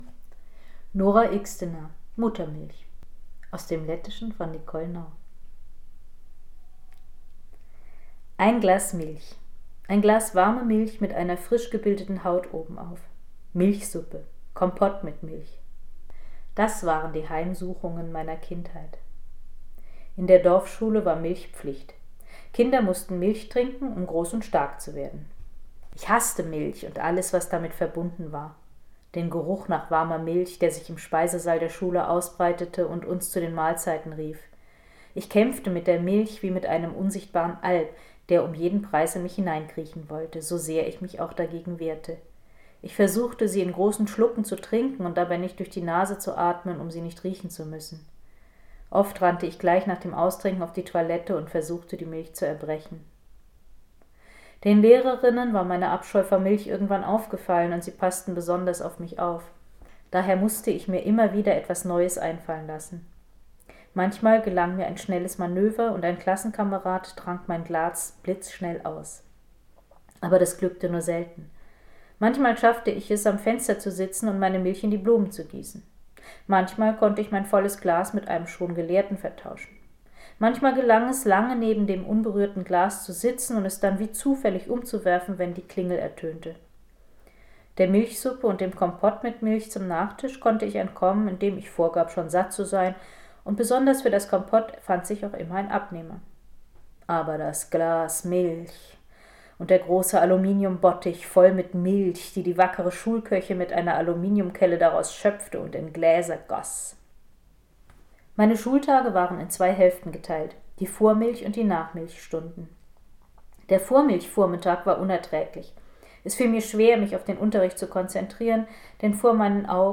Wenn Leipzig nicht liest, lese ich Euch eben mal was vor. Einen Ausschnitt aus Nora Ikstenas Roman Muttermilch.